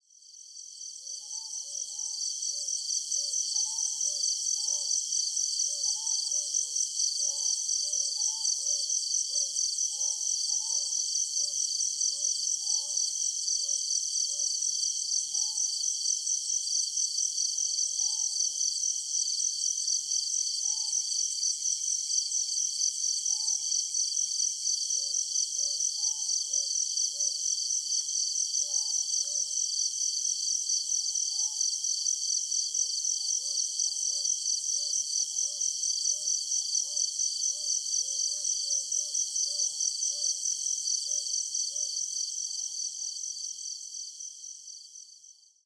This finally became possible in the summer of 2014 and I went there with a four-channel microphone setup.
feat. Northern boobook (Ninox japonica), Ryūkyū scops owl, high pitched sounds ‘maracas-like’ of Eiffinger tree frog, Ryūkyū scops owl, Ryūkyū
kajika frog (Buegeria japonica) and the repetitive calls of White-breasted waterhen.
Field Recording Series by Gruenrekorder